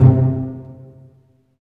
Index of /90_sSampleCDs/Roland LCDP13 String Sections/STR_Vcs Marc&Piz/STR_Vcs Pz.2 amb
STR PIZZ.08L.wav